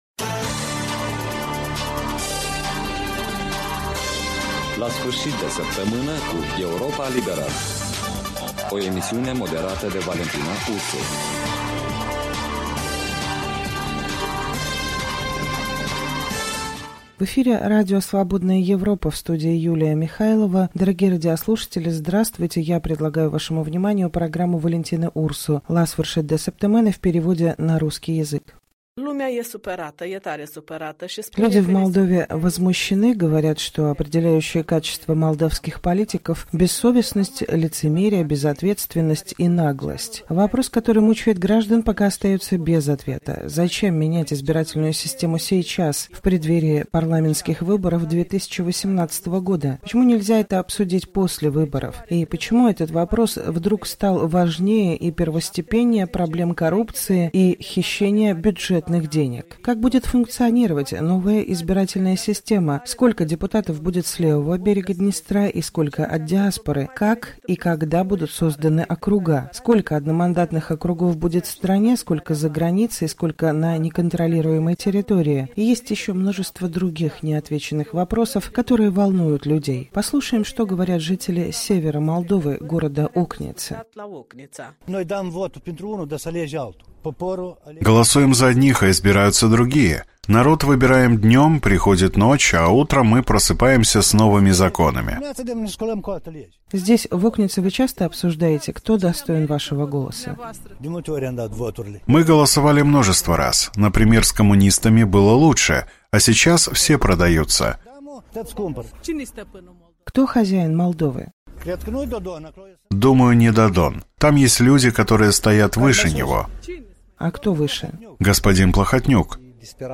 гость в студии